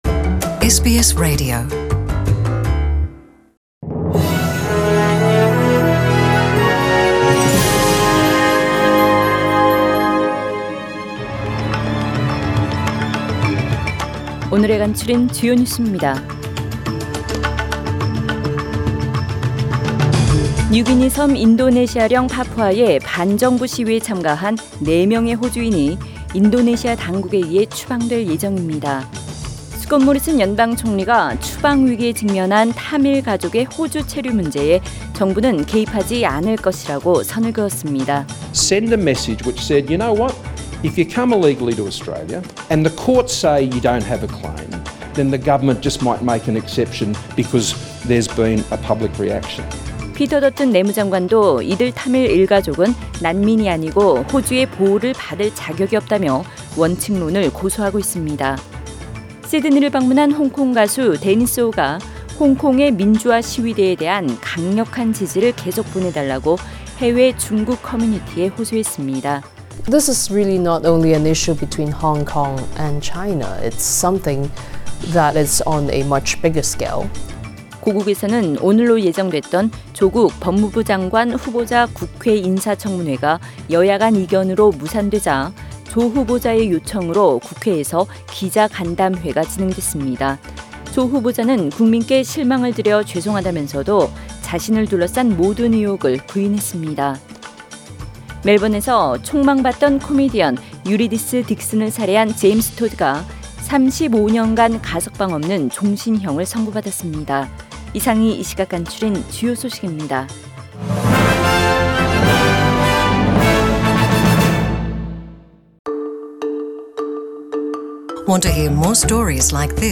SBS 한국어 뉴스 간추린 주요 소식 – 9월 2일 월요일